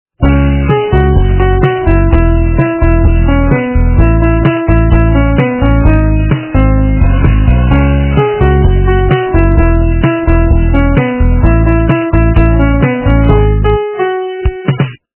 русская эстрада
полифоническую мелодию